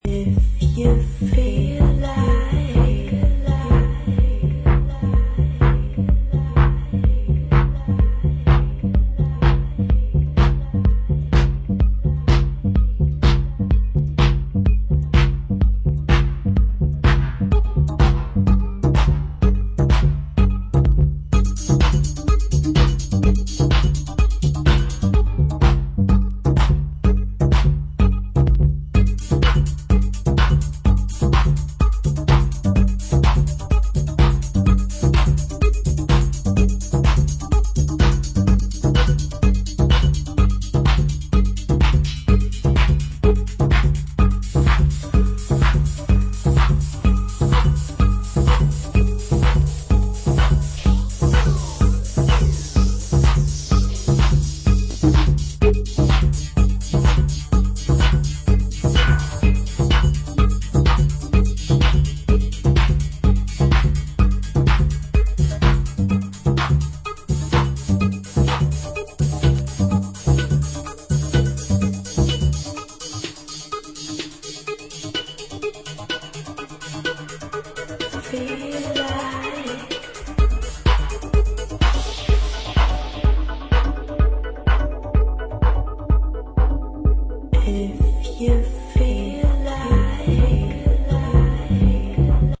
with rawer chicago sounds and 'catchy' vocals